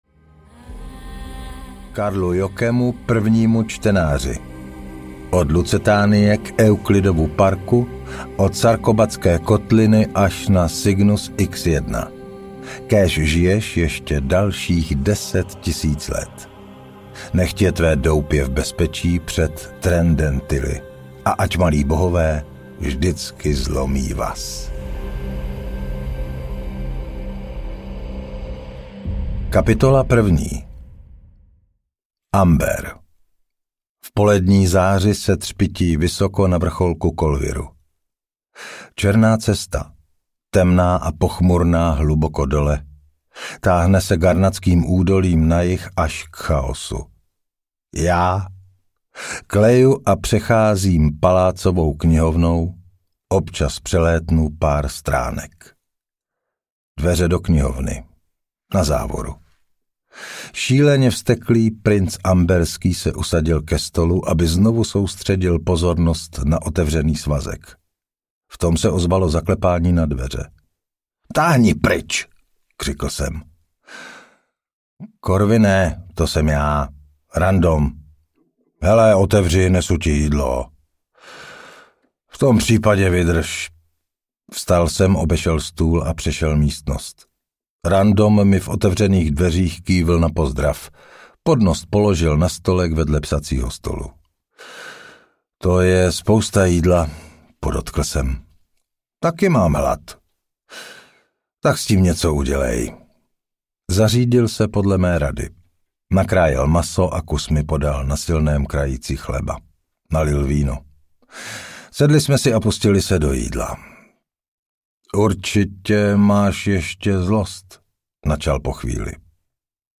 Amber 5 - Dvory Chaosu audiokniha
Ukázka z knihy